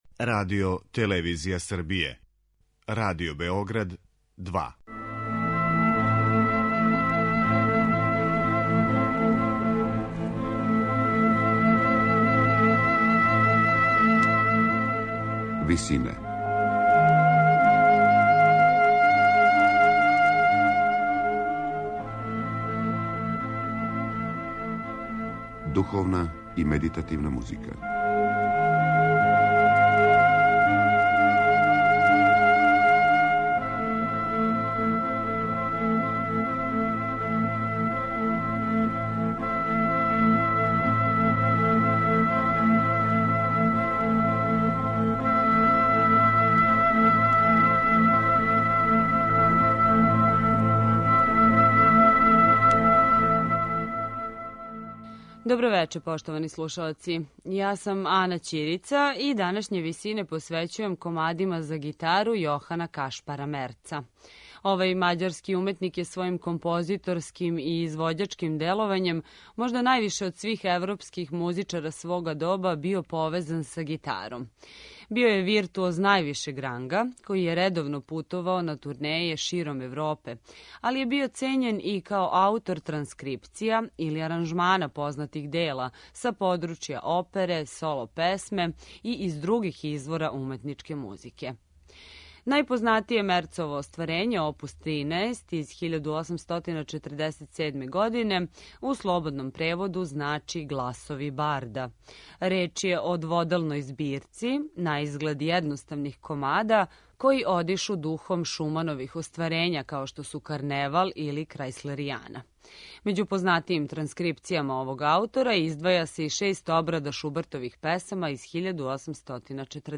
Музика за гитару